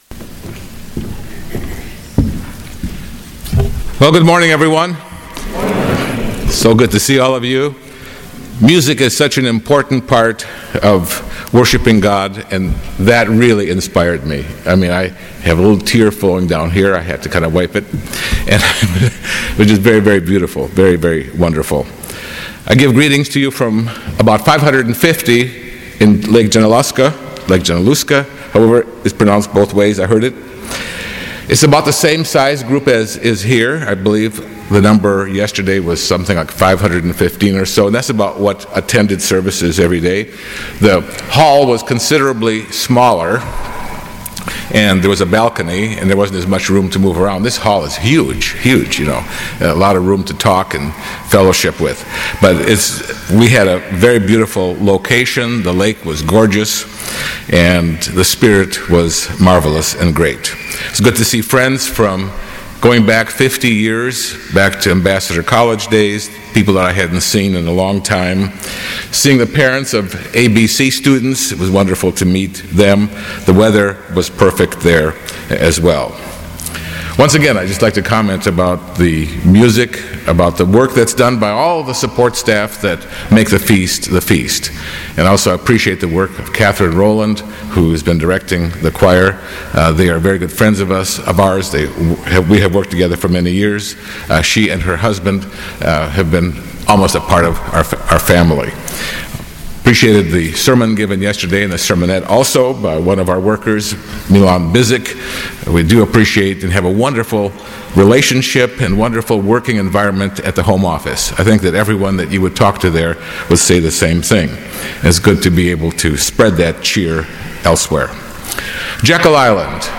This sermon was given at the Jekyll Island, Georgia 2017 Feast site.